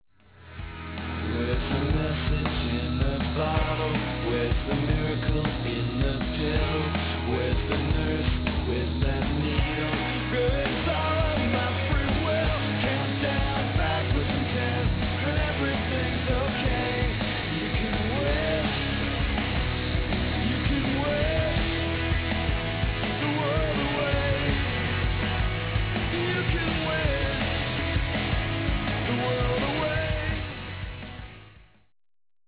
"pop record"